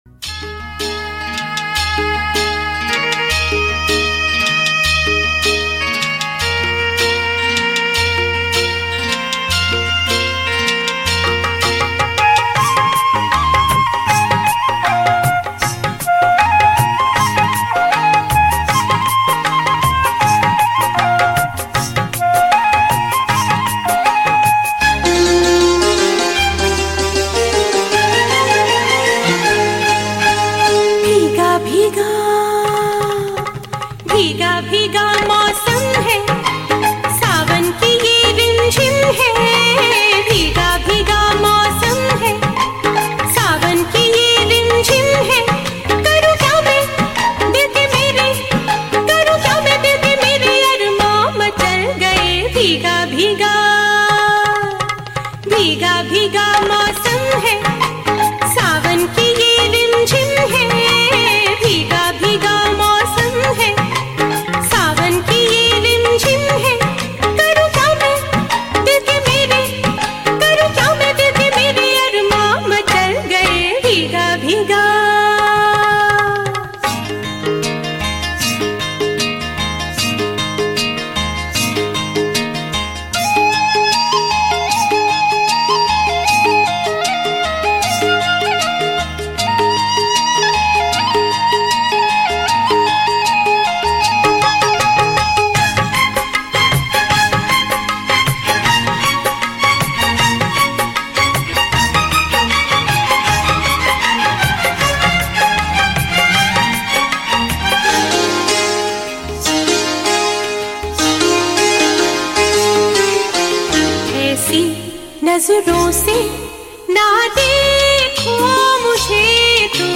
Hindi Romantic Hits